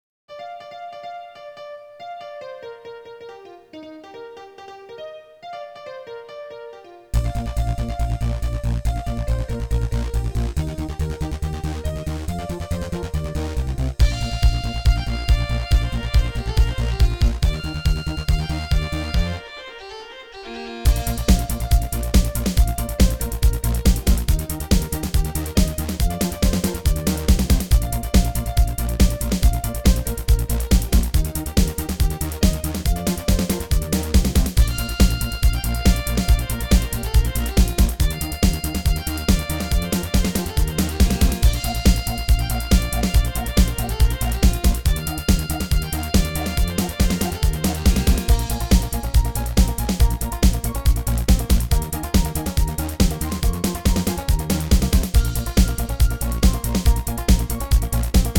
固件firm230328中，增加了新的“舞曲”功能，固件中给内置了30+首劲爆舞曲。
舞曲片段4